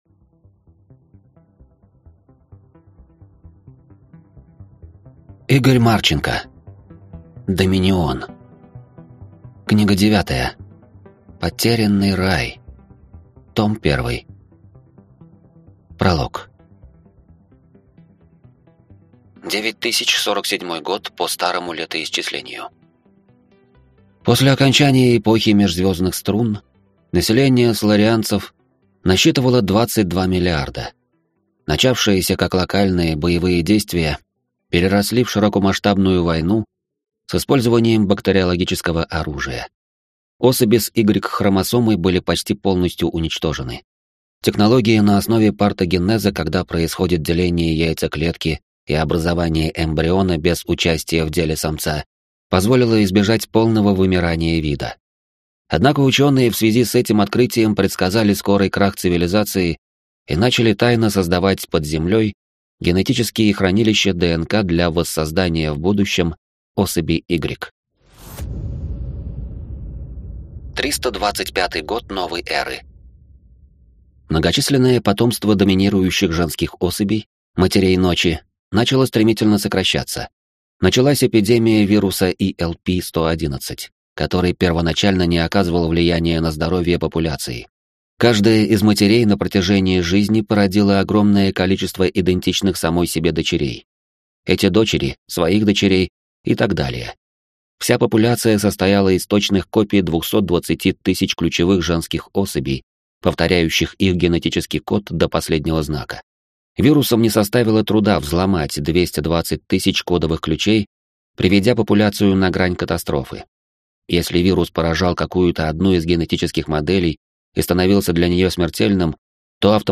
Аудиокнига Потерянный рай. Том 1 | Библиотека аудиокниг